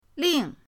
ling4.mp3